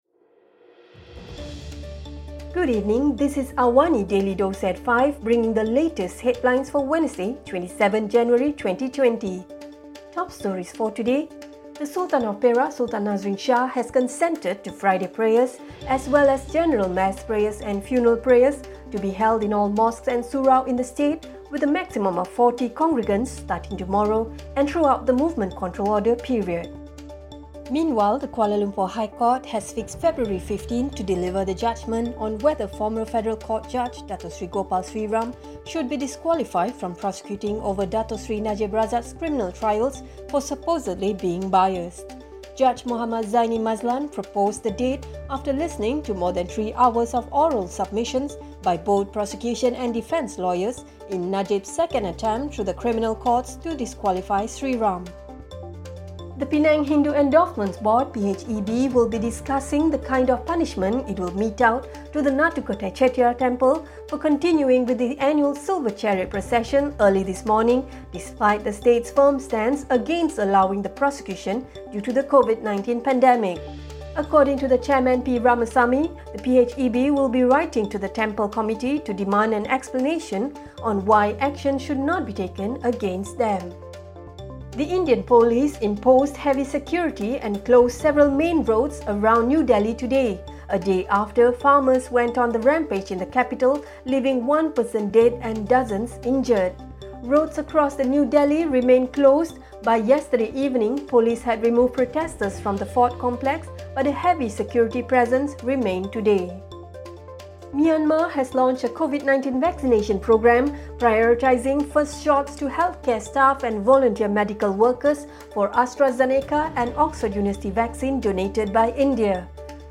Also, the launch News Showcase as early as next month seems to be Google's latest tactic in a high-profile campaign against the Australian government's planned legislation to make the company pay local news providers for content that appears in its search engine. Listen to the top stories of the day, reporting from Astro AWANI newsroom — all in 3-minutes.